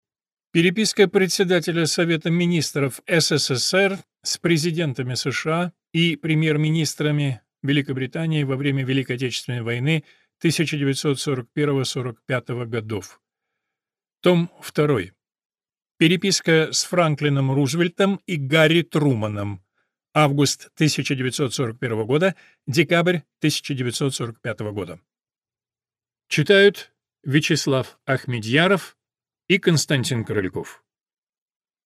Аудиокнига Переписка Председателя Совета Министров СССР с Президентами США и Премьер-Министрами Великобритании во время Великой Отечественной войны 1941–1945 гг. Том 2 | Библиотека аудиокниг